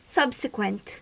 Ääntäminen
IPA : /ˈsʌb.sɪ.kwənt/